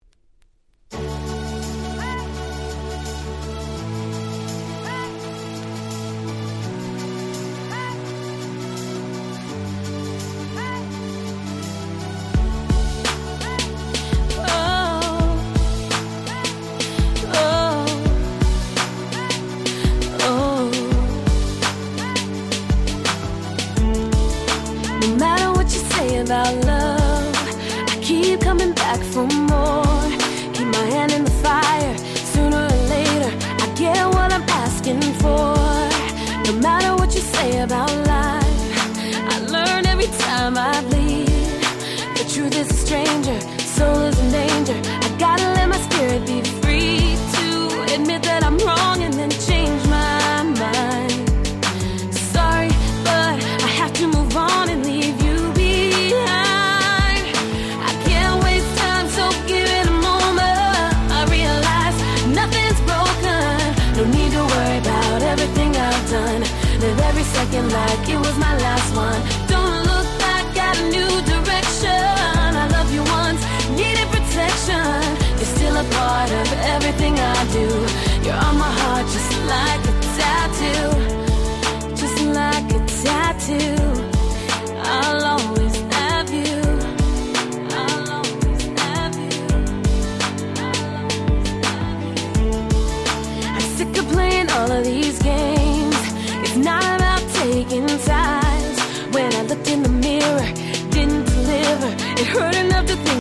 07’ Super Hit R&B !!